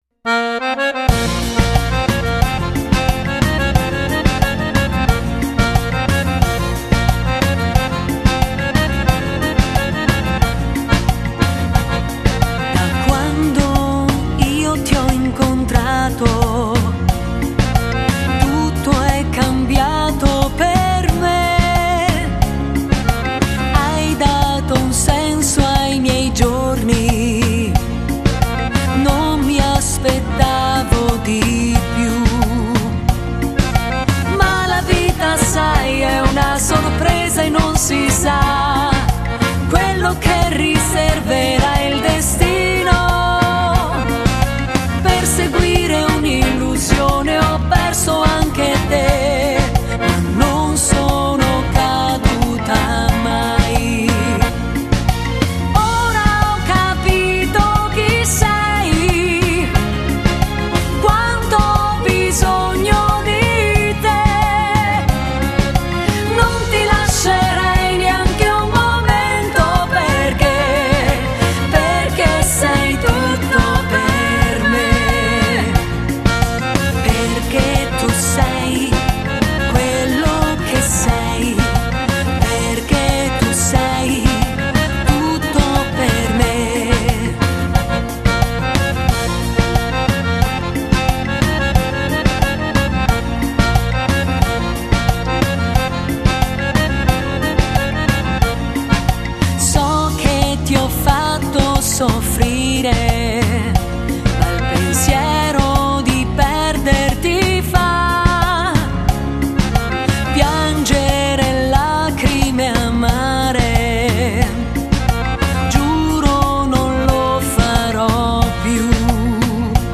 Genere: Cumbia